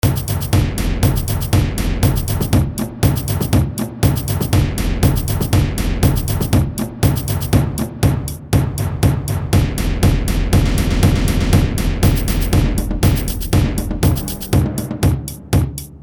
Etude 2.3 - percussion experiment.mp3